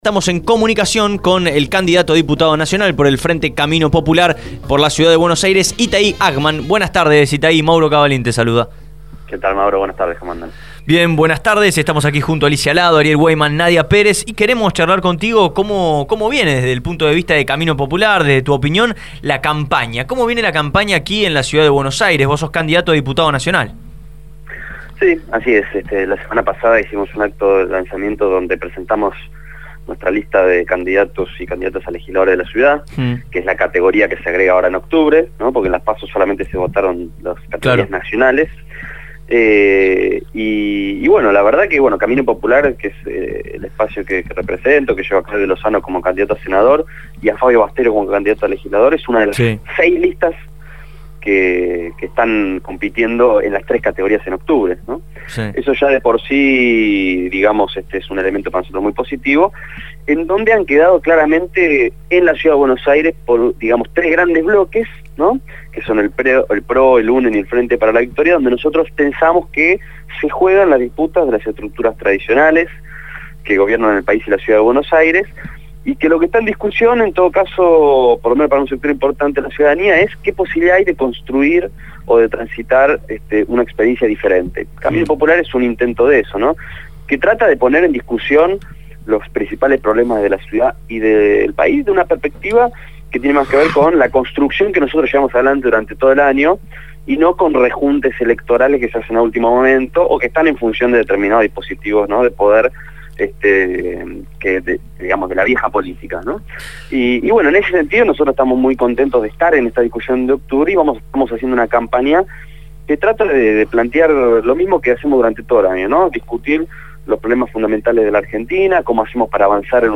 Itai Hagman, candidato a diputado nacional por Camino Popular, fue entrevistado en Abramos la Boca.